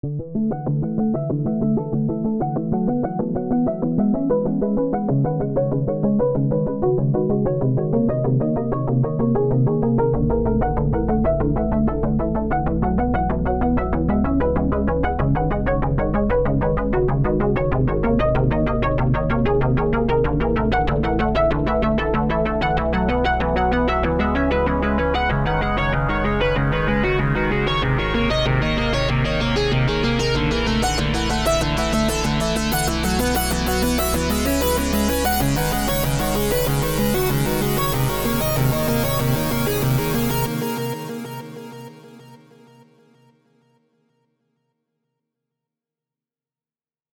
is this synthwave? idk lol
whoof whatever filter you’re using on that sounds real good